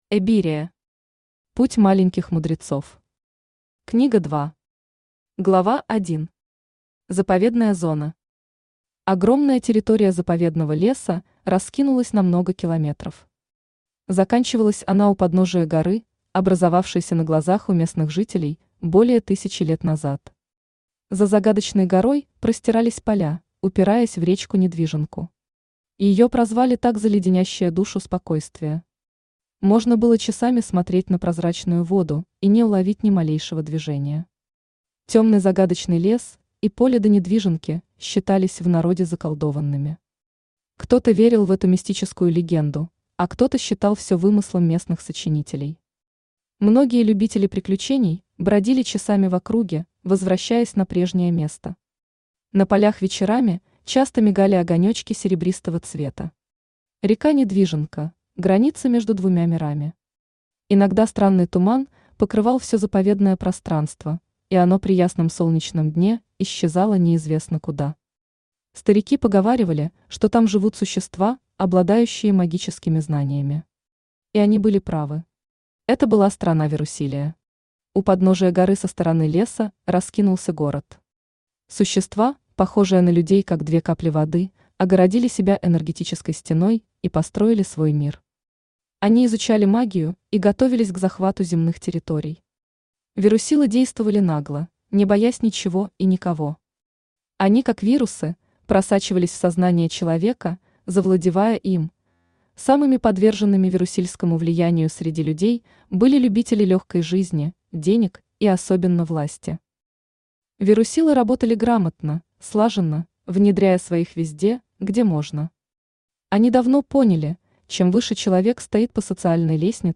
Аудиокнига Эбирия. Путь маленьких мудрецов | Библиотека аудиокниг
Путь маленьких мудрецов Автор Галина Луч Читает аудиокнигу Авточтец ЛитРес.